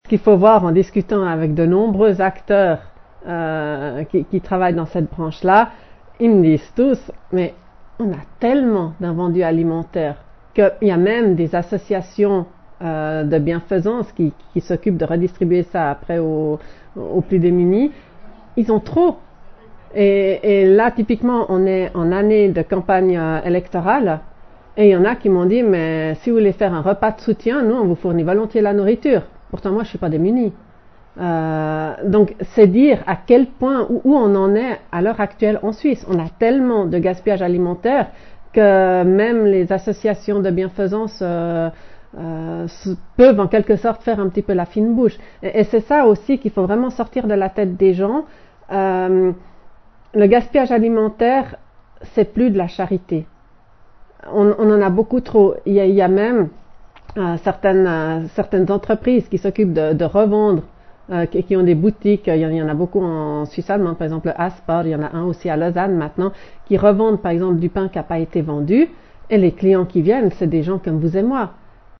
Audio : “Le gaspillage alimentaire, ce n’est pas de la charité“, Céline Weber (Conseil national, PVL)